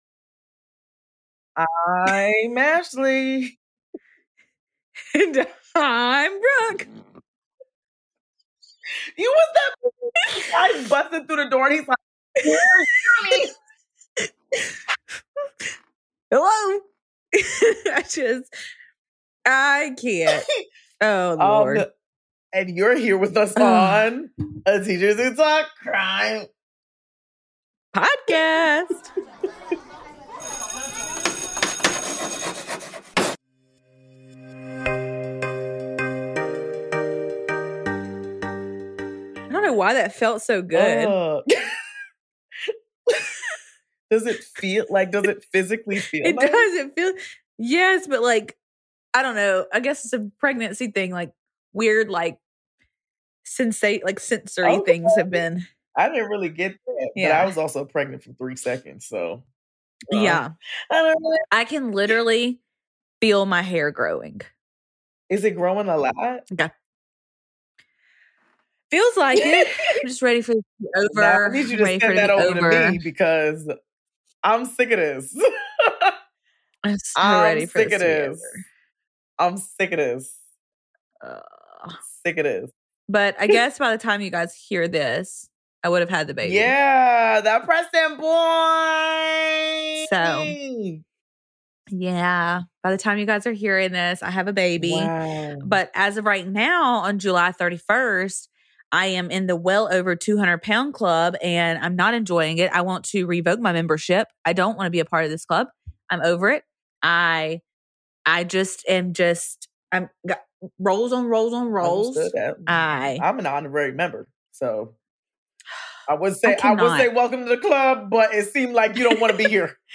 Dive into the lighter side of Teachers Talk Crime with this special blooper episode! It’s all about the flubs, laughter, and those "oh-no" moments that happen when we’re trying to sound smart about crime.